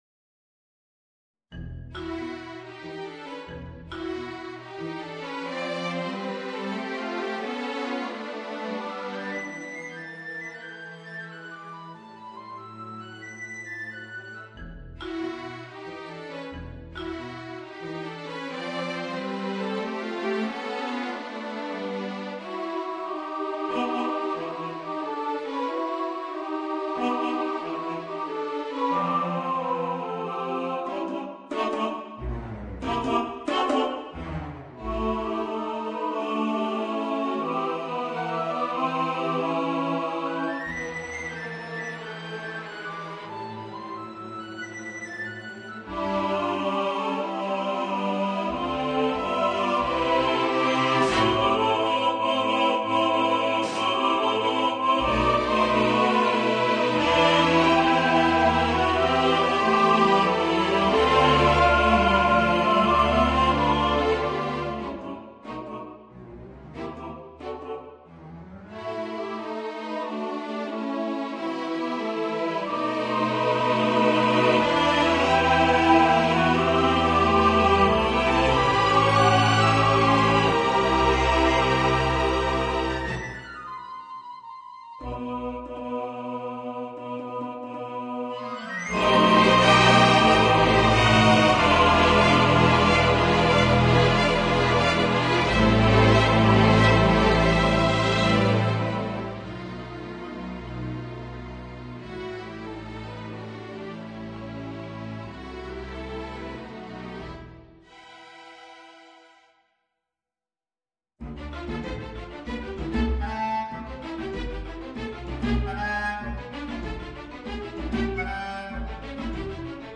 Soprano, Baritone, Chorus